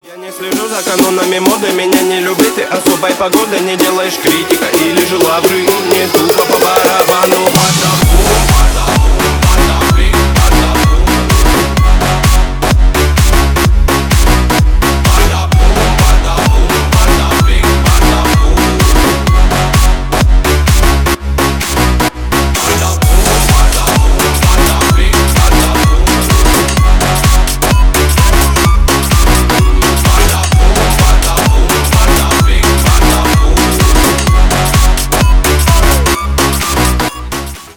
Ремикс
громкие # клубные